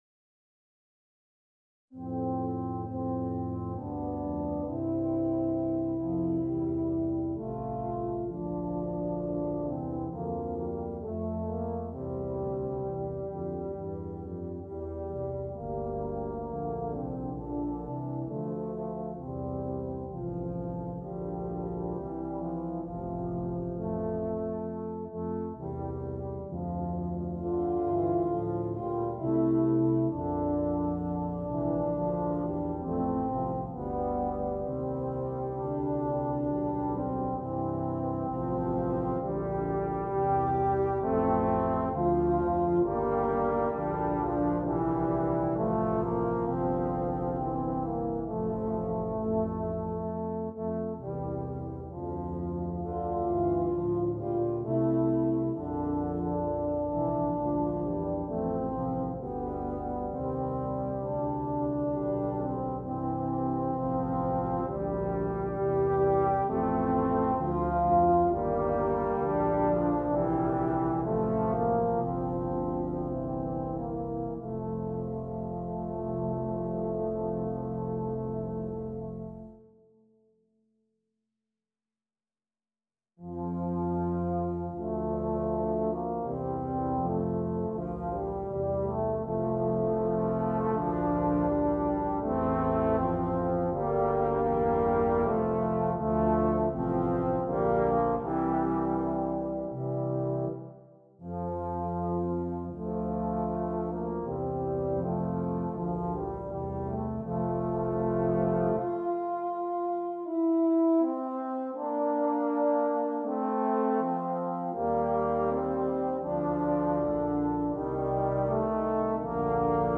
• 4 Tubas